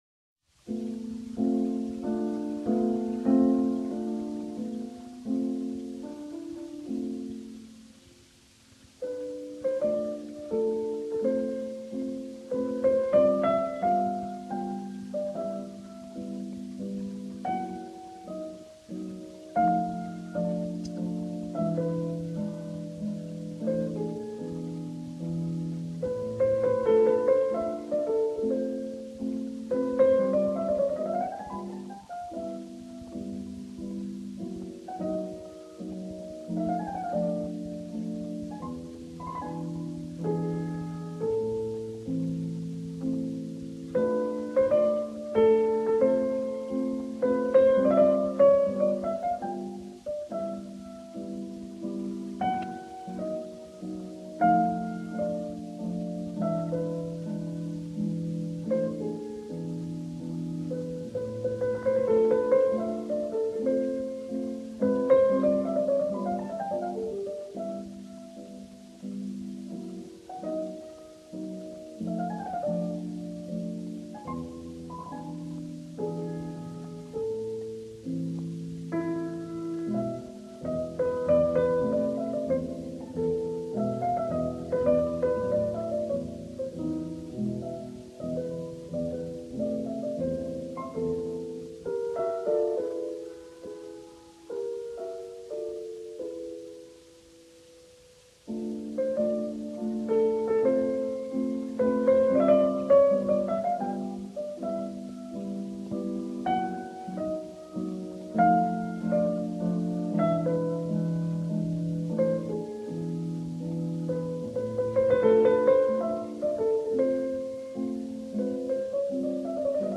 Walter Gieseking 1938 – Frédéric Chopin mazurka en la mineur Opus 17 n°4